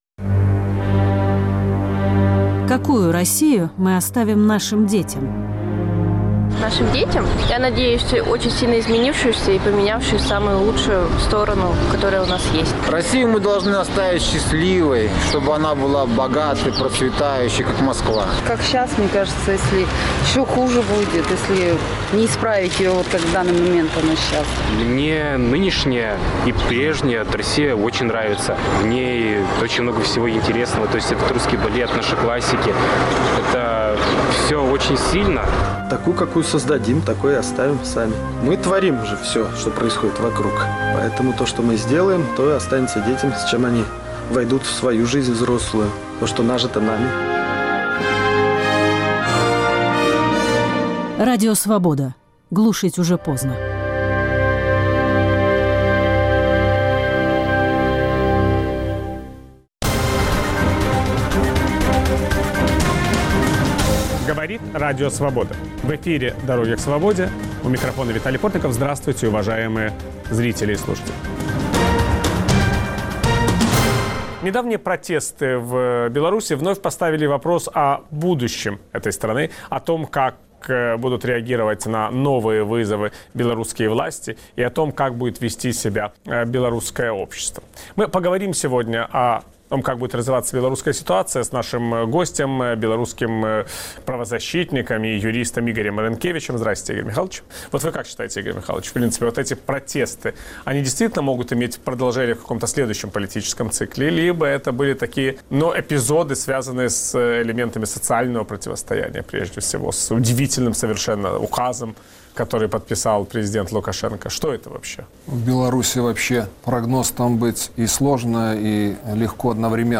Как меняется после недавних протестов ситуация в Беларуси? Виталий Портников беседует с белорусским юристом и правозащитником